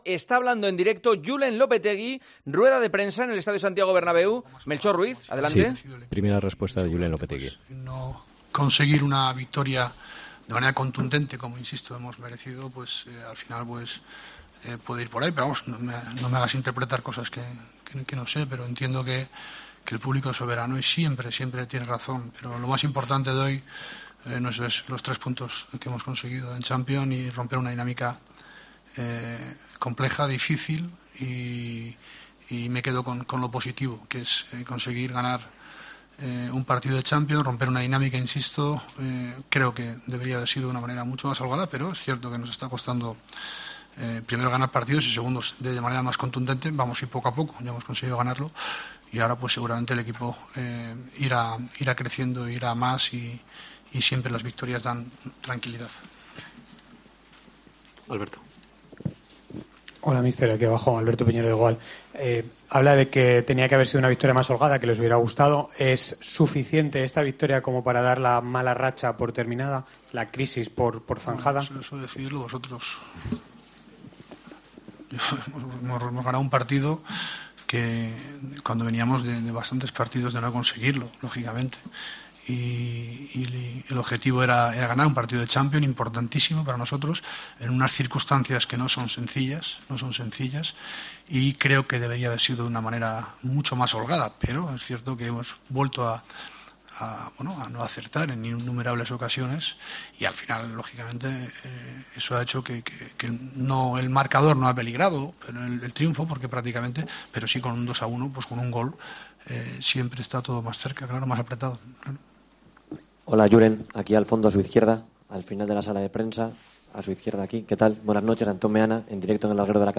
Julen Lopetegui compareció más aliviado en la rueda de prensa posterior al partido entre Real Madrid y Viktoria Plzen: " Me quedan lejos las palabras de Butragueño ", dijo en alusión al mensaje de Butragueño tras la derrota ante el Levante, que no se atrevió a ratificarle, " lo importante era ganar hoy ".